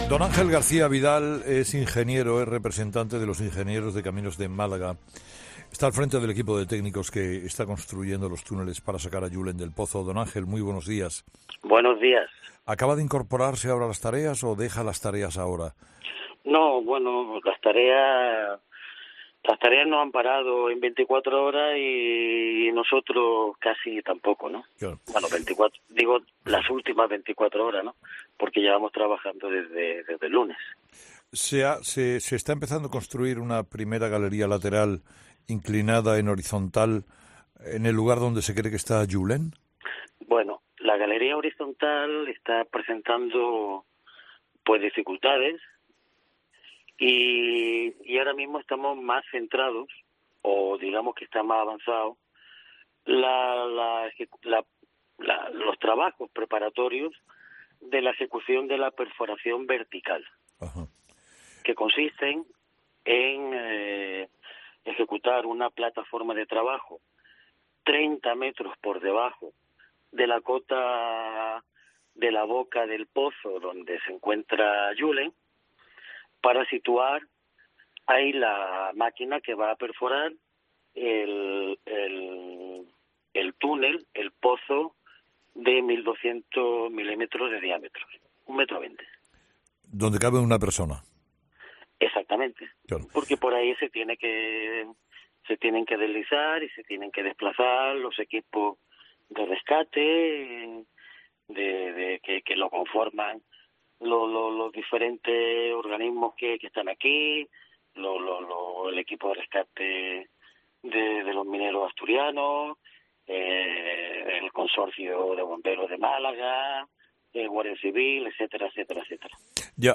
Entrevista
Un ingeniero de caminos que participa en los trabajos de rescate detalla en COPE las labores que se están haciendo para sacar al menor del pozo